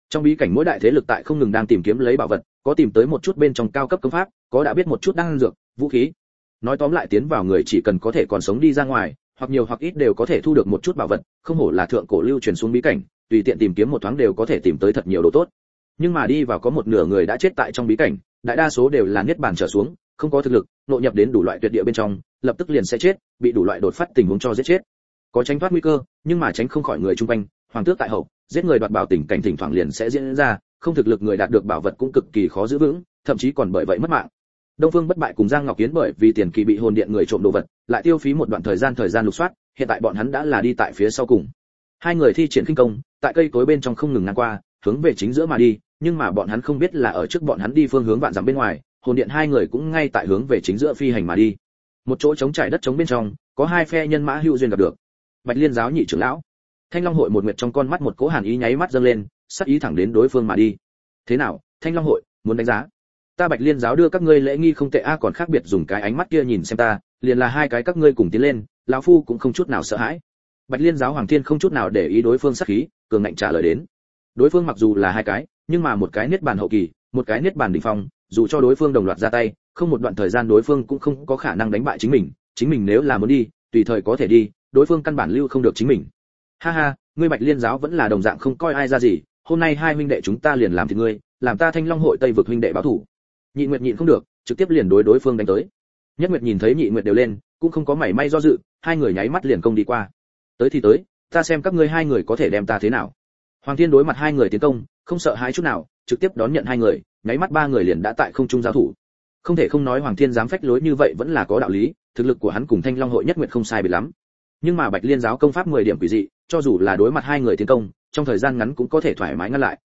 Triệu Hoán Chi Võ Hiệp Vô Song Audio - Nghe đọc Truyện Audio Online Hay Trên AUDIO TRUYỆN FULL